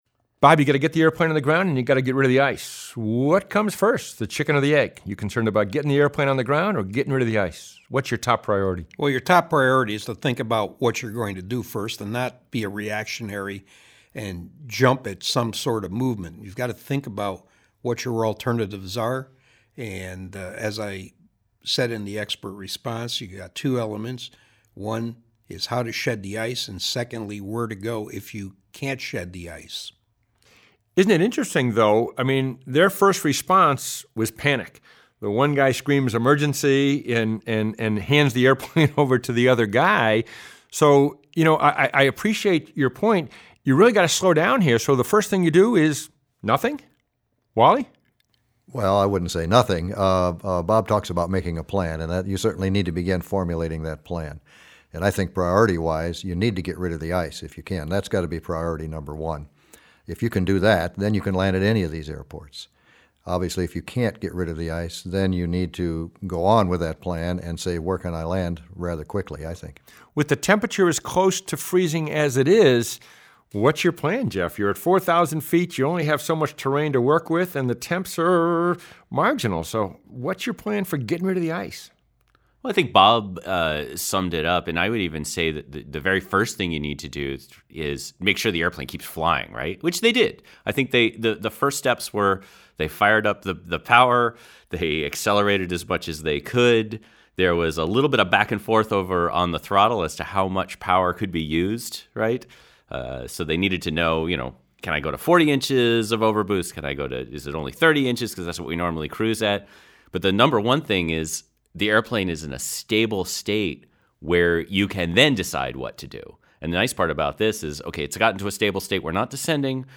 ice_fishing_roundtable.mp3